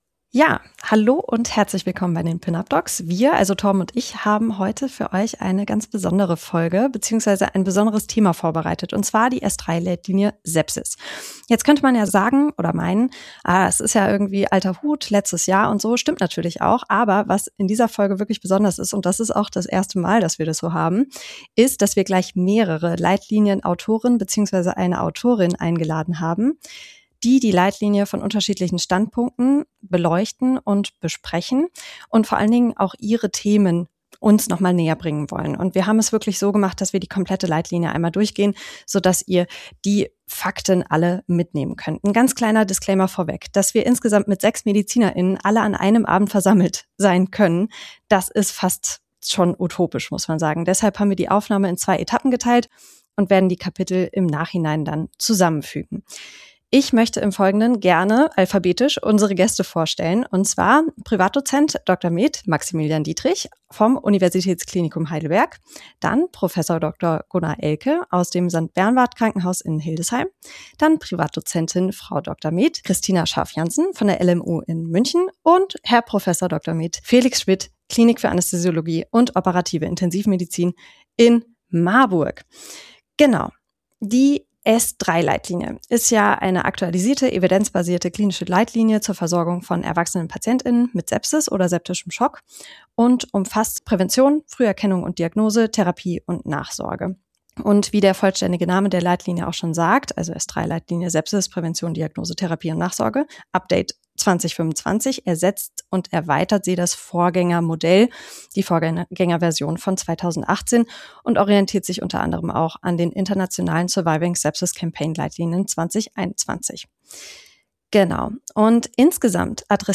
Hier kommt unsere Sonderfolge zur S3-Leitlinie Sepsis mit einer Leitlinien-Autorin und drei Leitlinien-Autoren haben wir für Euch die Leitlinie zusammengefasst und können spannende Einblicke in die Entscheidungsfindung der Autor*innen geben.